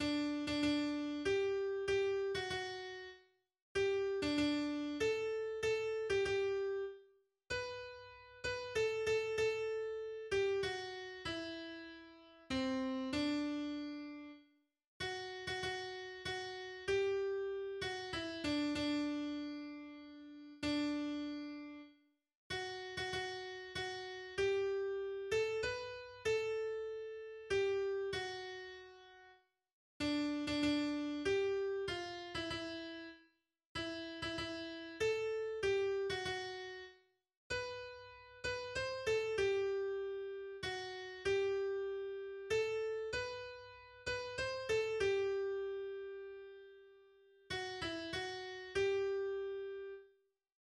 Schweizer Landeshymne seit 1961